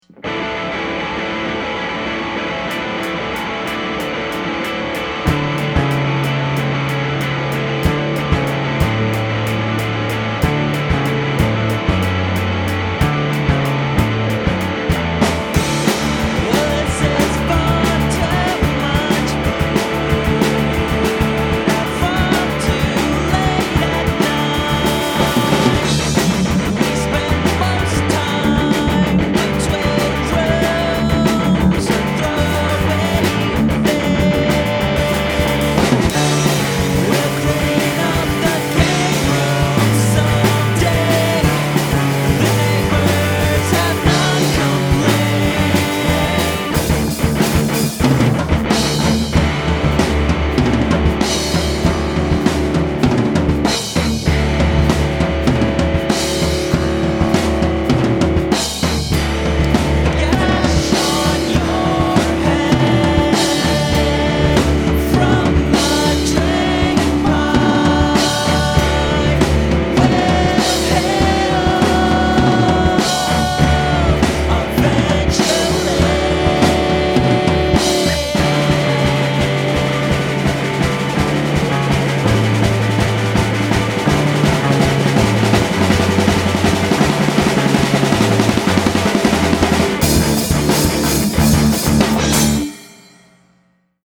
recorded at bartertown studios